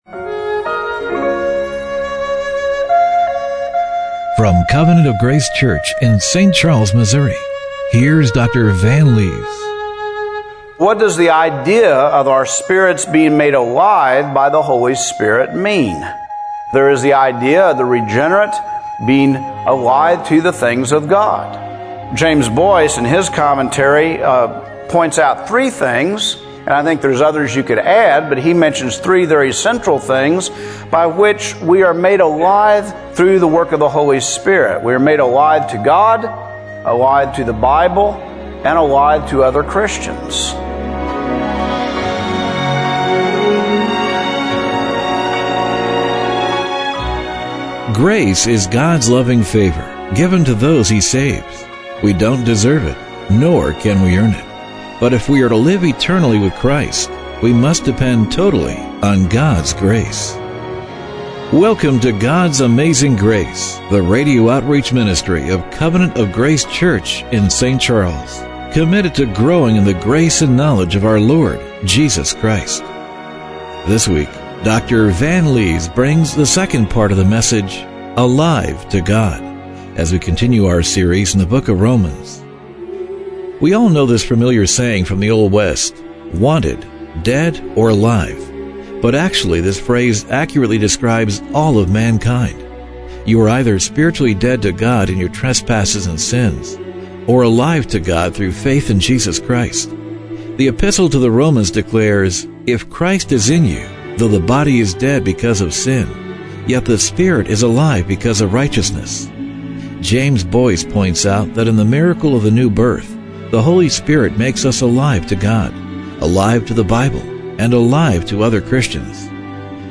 Romans 8:9-13 Service Type: Radio Broadcast Are you spiritually dead to God in your trespasses and sins -- or alive to God through faith in Jesus Christ?